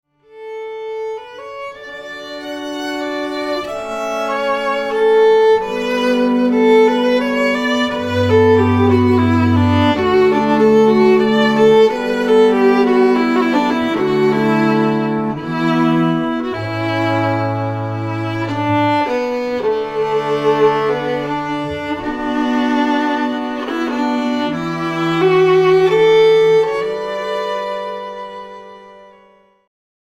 Bass
Fiddle
Cello
guitar